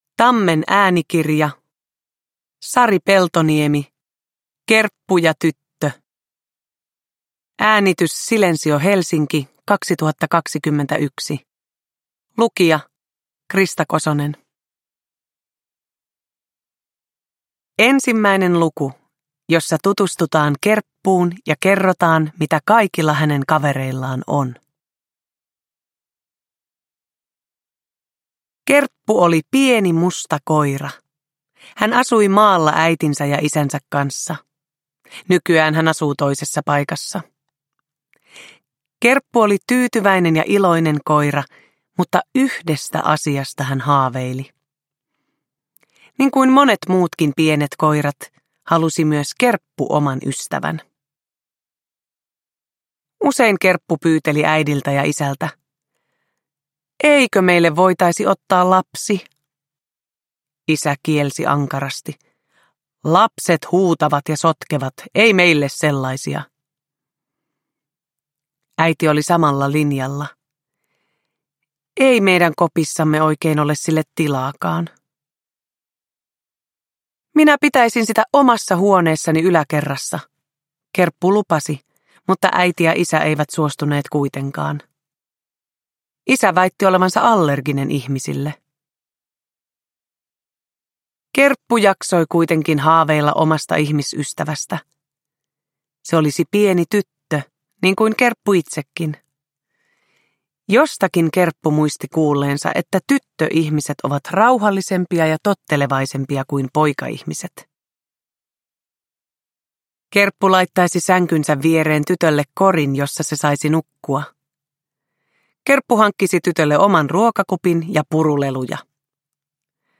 Kerppu ja tyttö – Ljudbok – Laddas ner
Uppläsare: Krista Kosonen